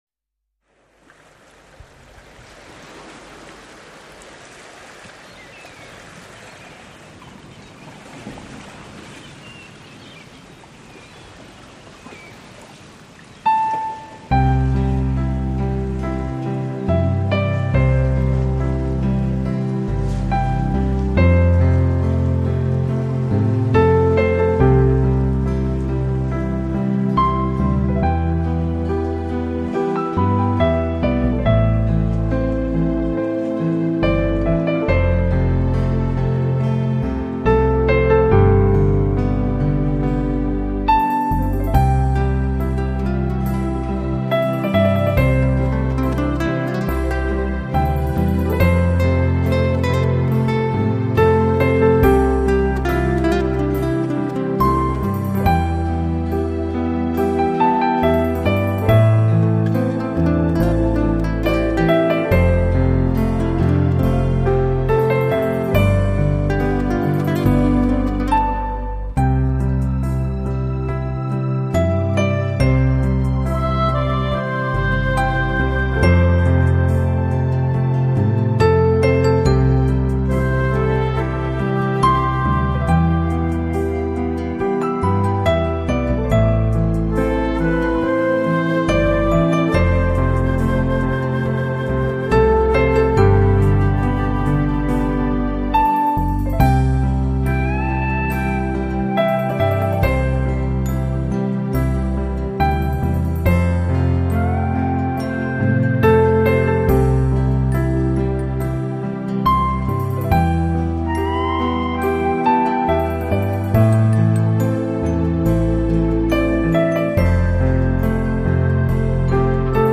New Age, Piano  2006